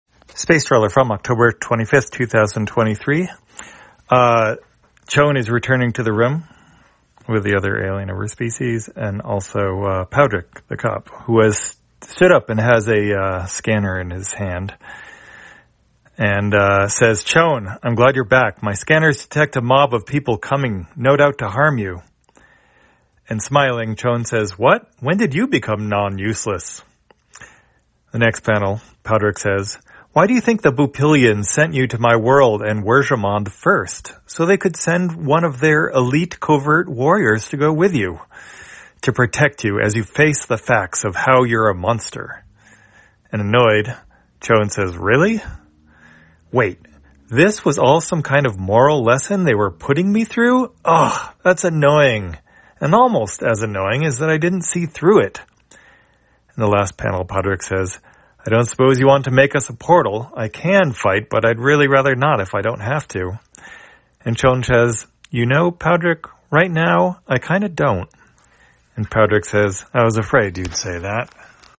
Spacetrawler, audio version For the blind or visually impaired, October 25, 2023.